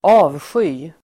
Uttal: [²'a:vsjy:]